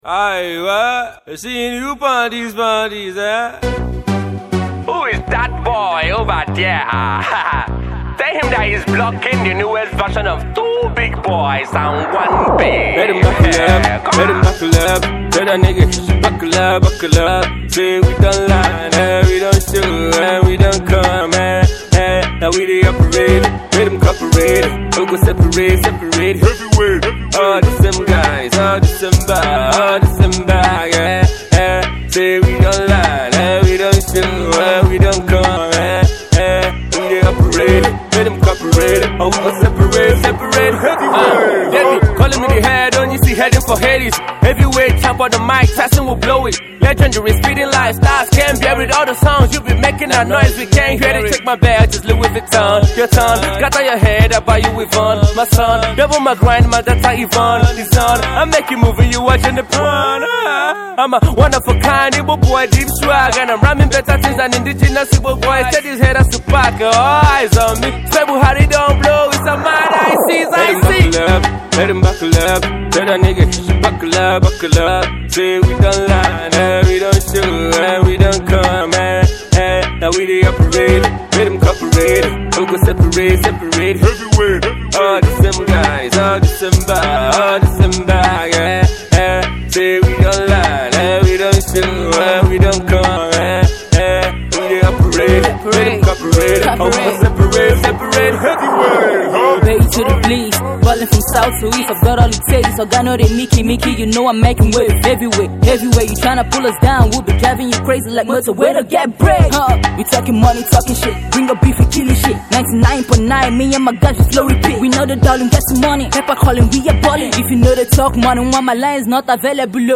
a mid tempo jam you can Vibe to